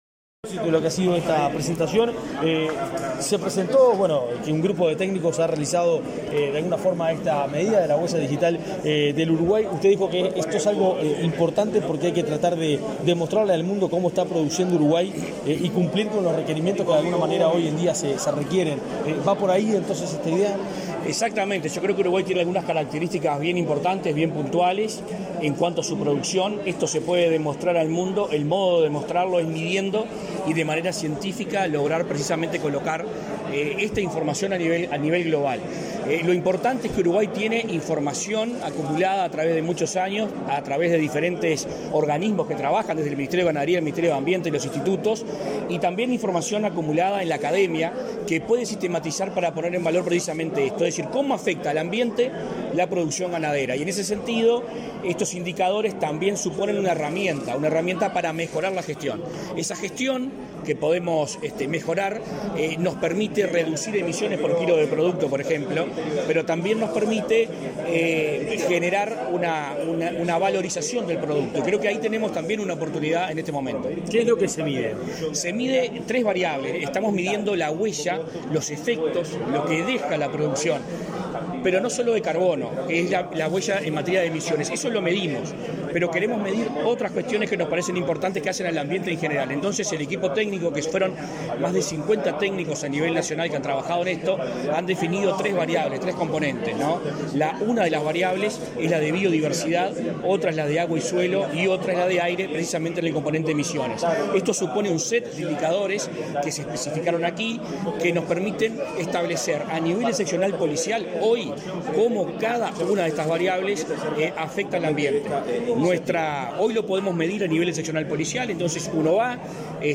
Declaraciones de prensa del ministro de Ambiente, Adrián Peña
Declaraciones de prensa del ministro de Ambiente, Adrián Peña 11/10/2022 Compartir Facebook X Copiar enlace WhatsApp LinkedIn Tras participar en la presentación de la Huella Ambiental de la Ganadería del Uruguay, este 11 de octubre, el ministro de Ambiente, Adrián Peña, realizó declaraciones a la prensa.